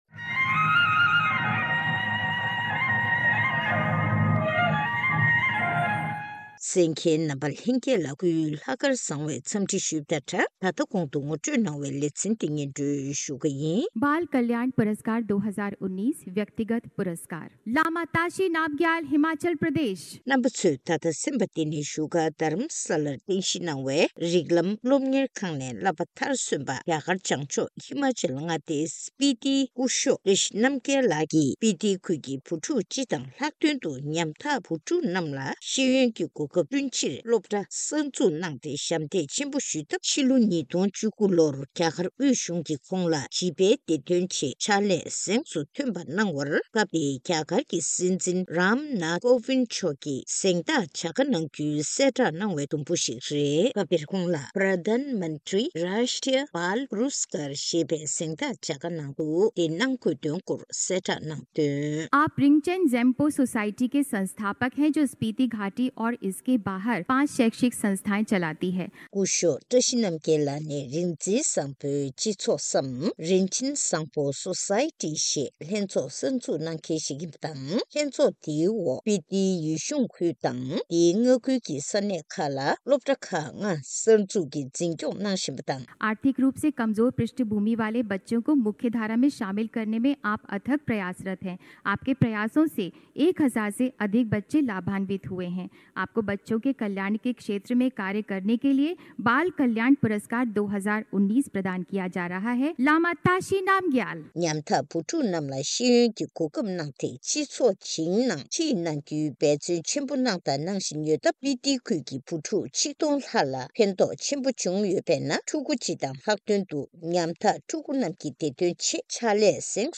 གནས་འདྲི་ཞུས་ནས་གནས་ཚུལ་ཕྱོགས་བསྒྲིགས་ཞུས་པ་ཞིག་གསན་རོགས་གནང་།